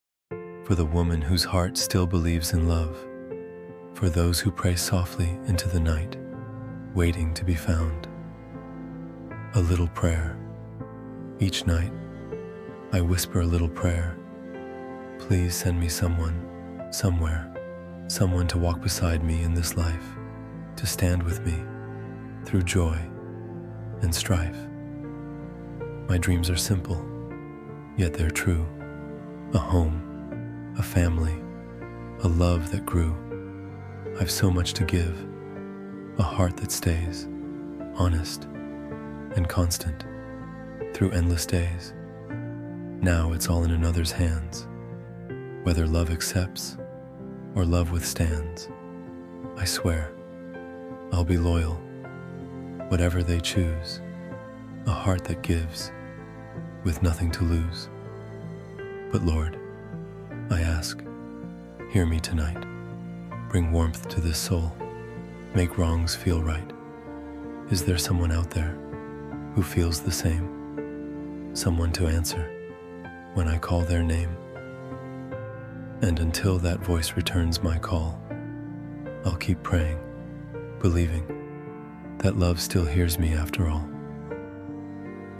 A-Little-Prayer-–-Love-Poem-Spoken-Word-MP3.mp3